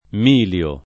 milio
vai all'elenco alfabetico delle voci ingrandisci il carattere 100% rimpicciolisci il carattere stampa invia tramite posta elettronica codividi su Facebook milio [ m & l L o ] (antiq. o pop. miglio ) s. m. («cisti»)